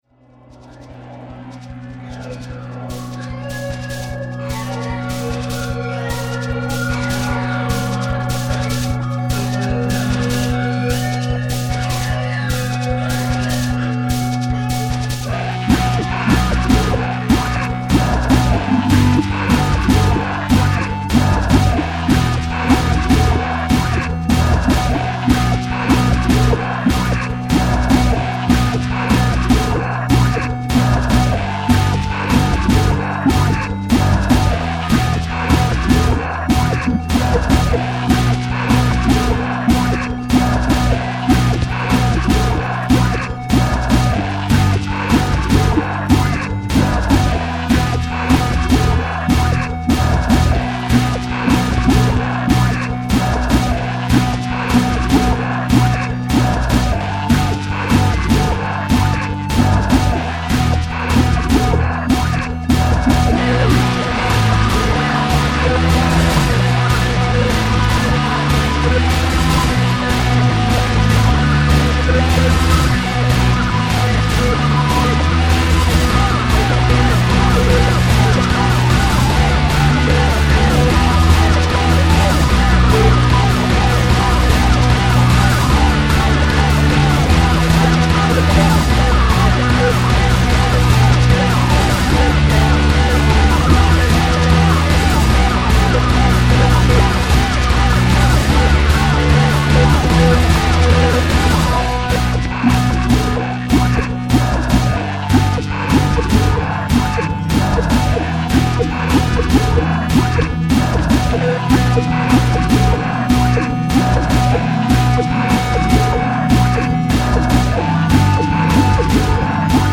At the time, my guitar had no strings, so all the stuff that sounds like guitar (except the horrible wibbly noises at the start – a background to another song, xenowhatsit in action) is actually a bass. Vocals performed by one of those little gizmo’s that speak what you type into it and made sinister by messing about with it.
But then I have a big soft spot in the head for thumpy industrial things; for an old-school tool like moi Skinny Puppy & FLA came to mind, but heyyy … that’s truly the sparkle of Doing Your Own Thing shining through.